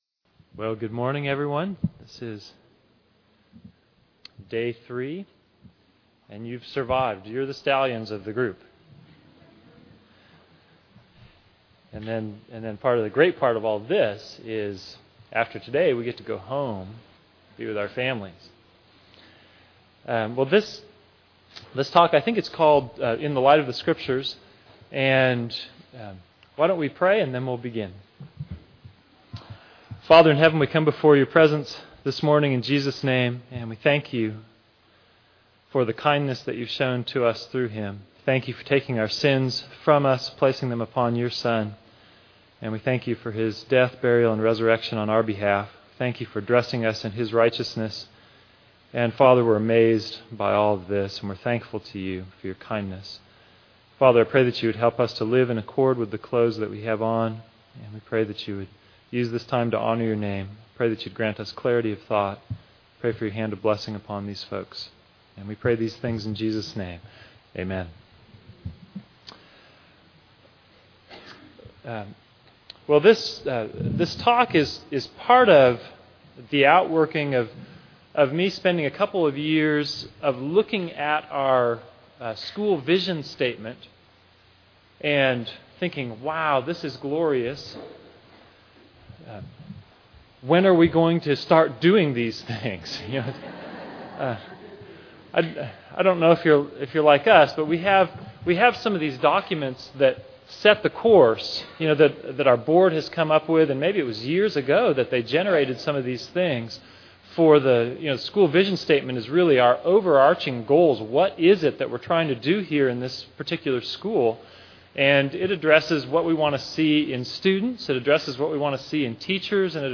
2009 Workshop Talk | 0:59:43 | All Grade Levels, Theology & Bible